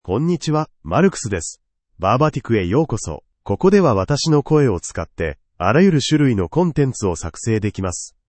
MarcusMale Japanese AI voice
Marcus is a male AI voice for Japanese (Japan).
Voice sample
Listen to Marcus's male Japanese voice.
Marcus delivers clear pronunciation with authentic Japan Japanese intonation, making your content sound professionally produced.